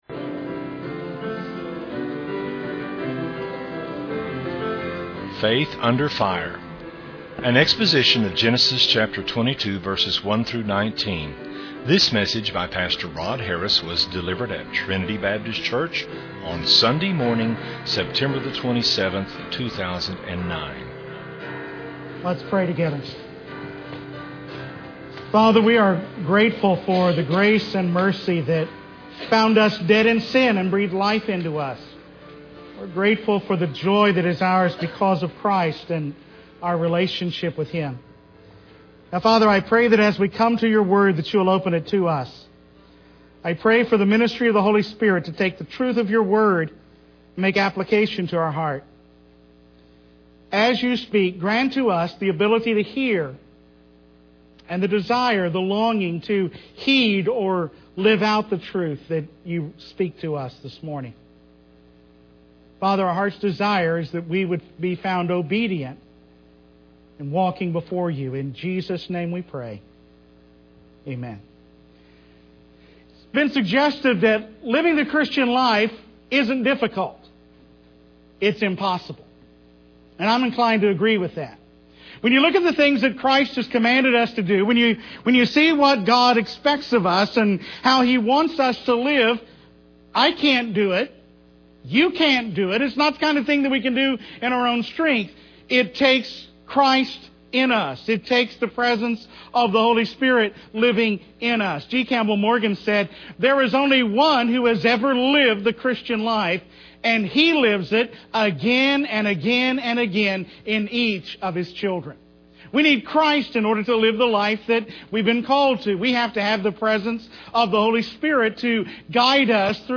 delivered at Trinity Baptist Church on Sunday morning, September 27, 2009.